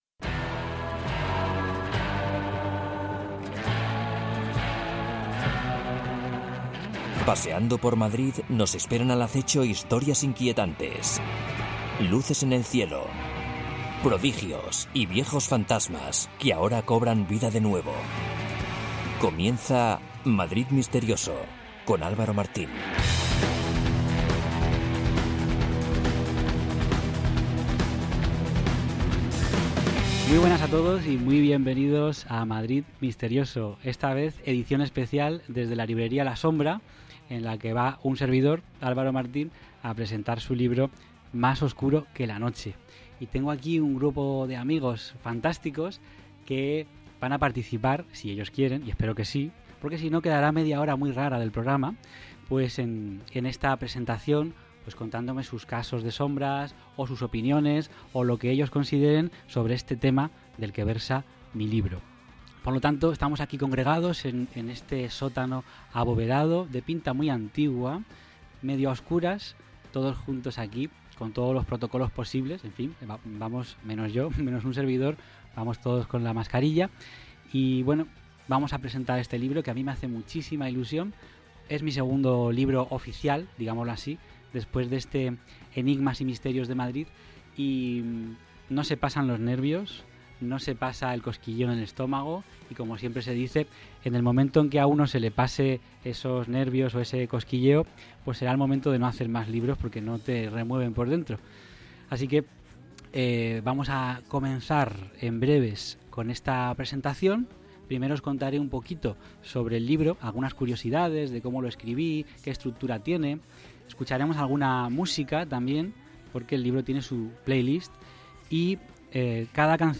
Programa especial grabado en la librería La Sombra (C\ San Pedro 20)